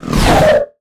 MAGIC_SPELL_Overdrive_mono.wav